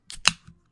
蒸汽
描述：打开一瓶苏打水，产生嘶嘶声。
标签： 蒸汽 释放 嘶嘶声 压力 空气 液压 苏打水 蒸汽朋克 开口
声道立体声